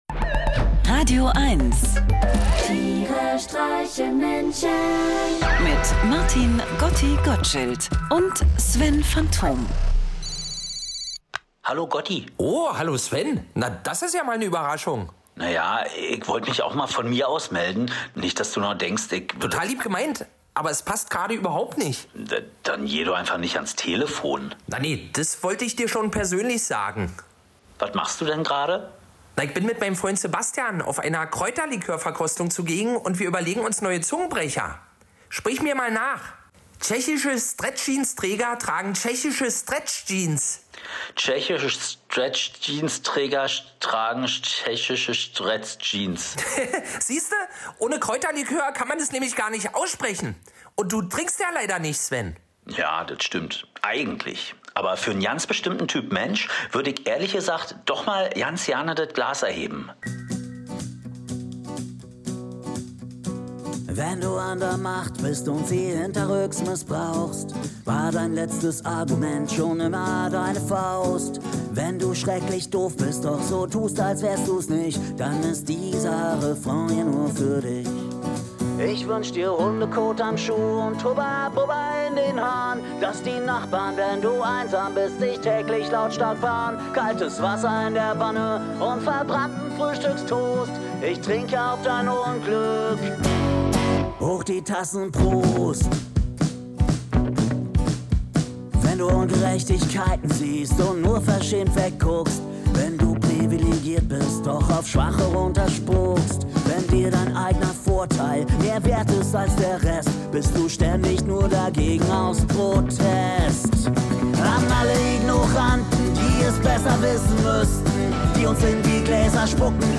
Einer liest, einer singt und dabei entstehen absurde, urkomische, aber auch melancholische Momente.
Comedy